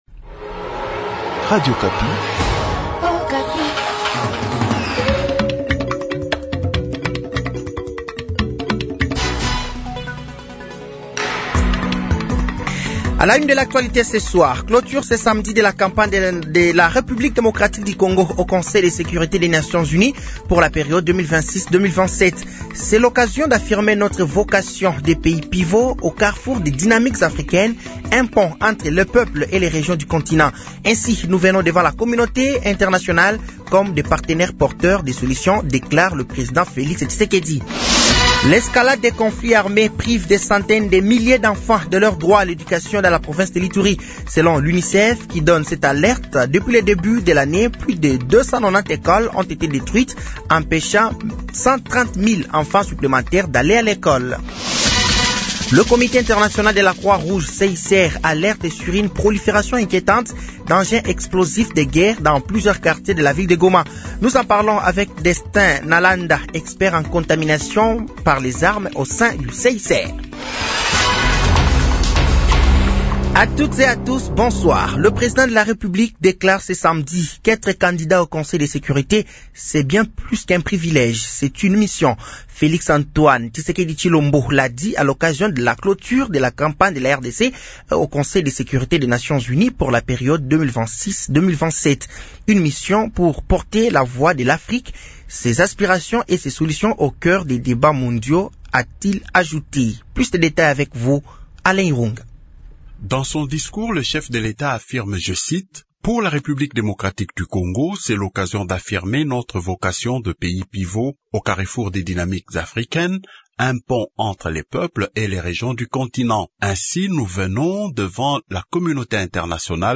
Journal français de 18h de ce samedi 31 mai 2025